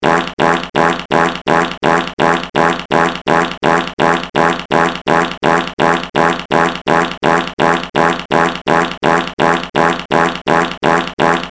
11 Secs Of Farts Téléchargement d'Effet Sonore
11 Secs Of Farts Bouton sonore